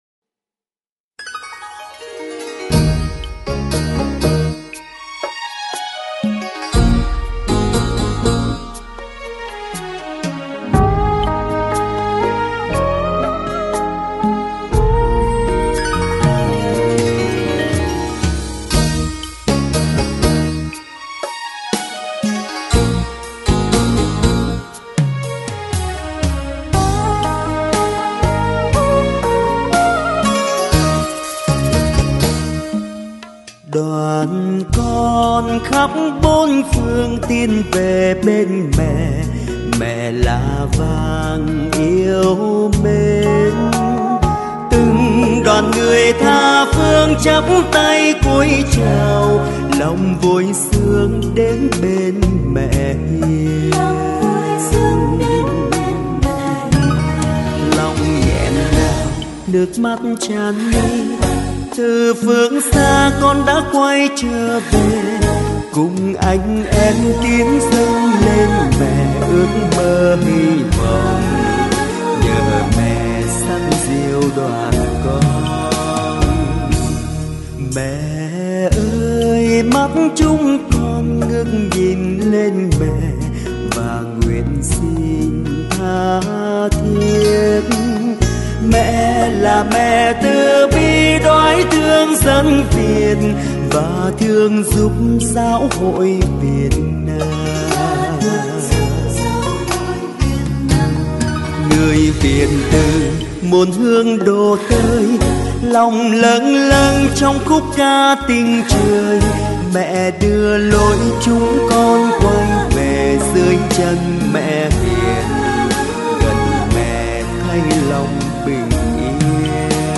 Trang chủ  Nhạc Thánh Ca